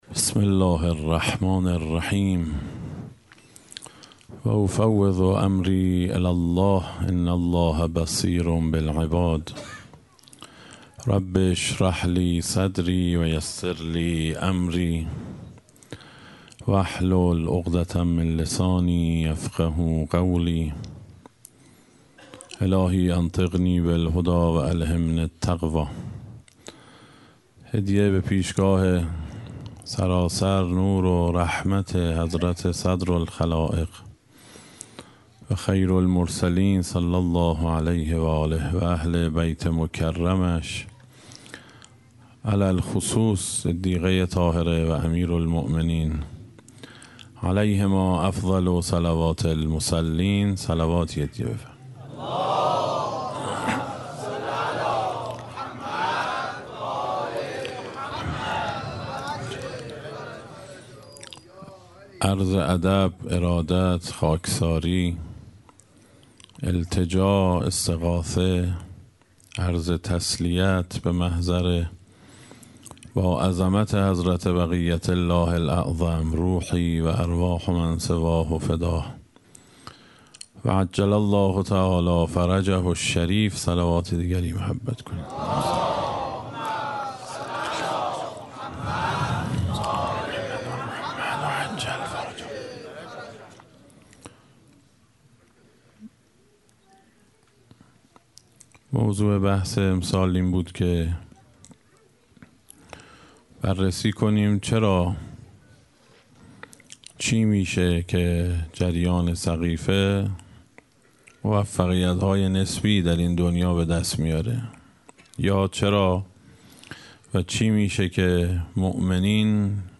اشتراک گذاری دسته: الهیات شکست , حضرت فاطمه سلام الله علیها , سخنرانی ها قبلی قبلی الهیات شکست؛ فاطمیه اول – جلسه هشتم از ده جلسه بعدی الهیات شکست؛ فاطمیه اول – جلسه دهم از ده جلسه (آخرین جلسه) بعدی